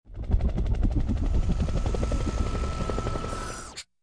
audio: Converted sound effects
ENC_propeller_in.ogg